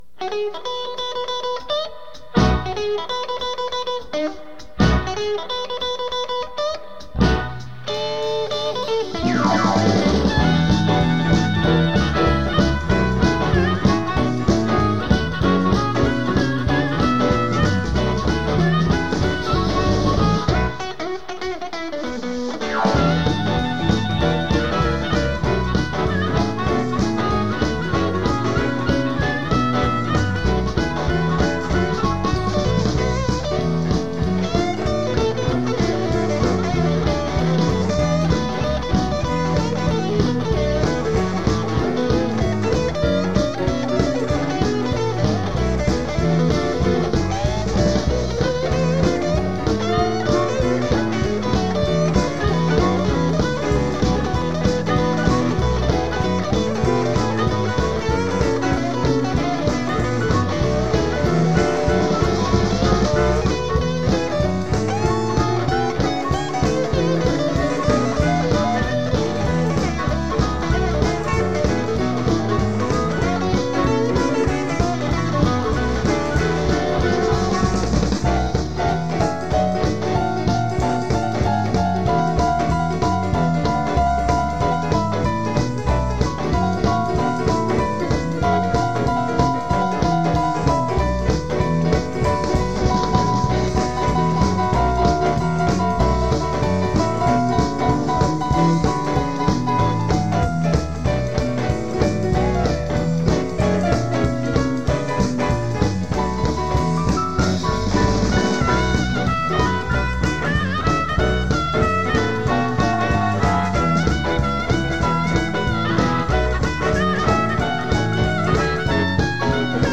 blues tunes